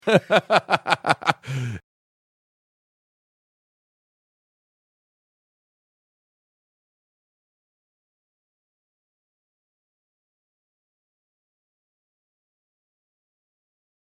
LAUGH 2